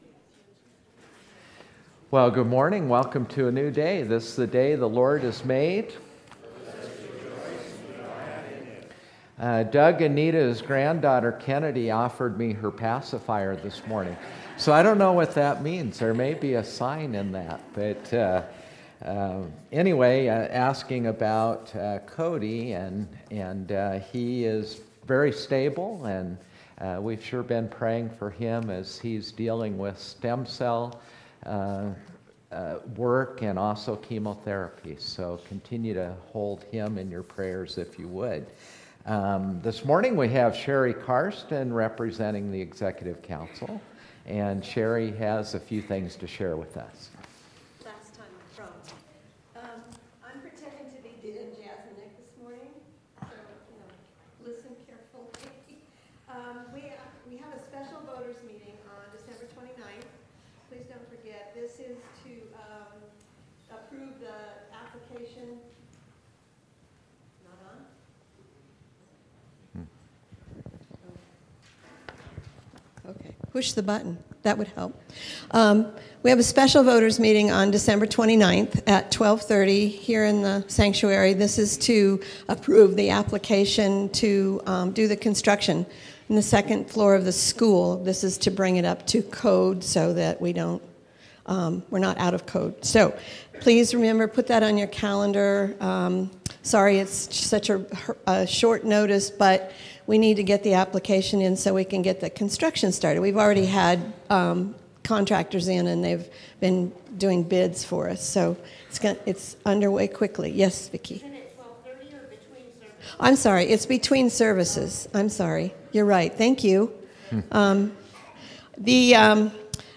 Traditional Worship 12/22